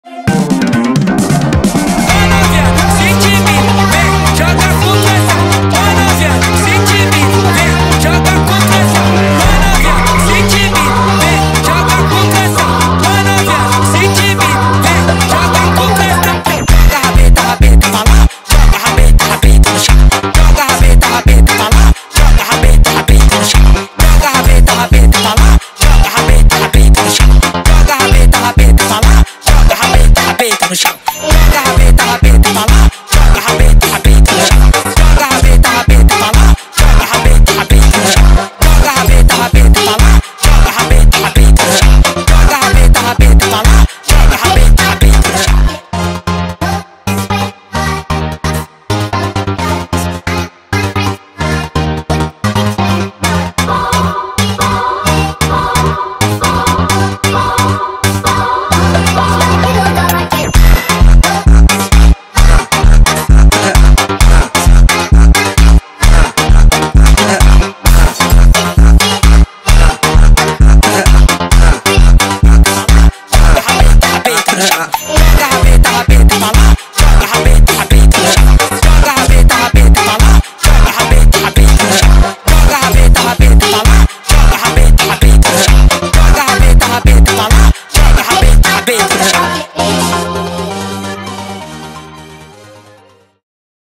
با ریتمی سریع شده
فانک